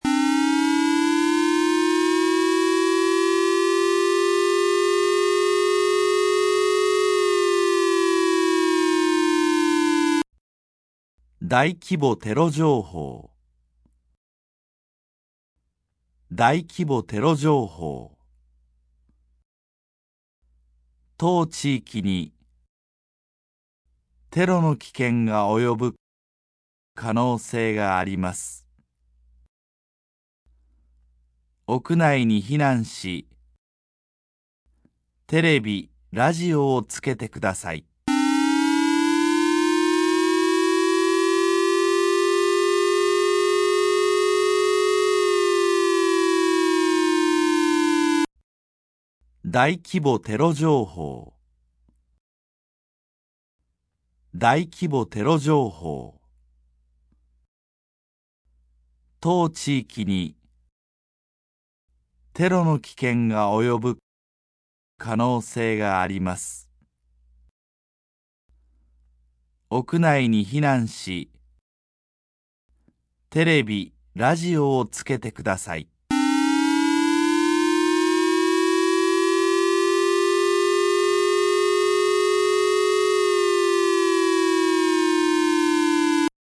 燕市に弾道ミサイルなどの武力攻撃等が発生あるいは予測される場合に、防災行政無線でお知らせいたします
• 《有事サイレン》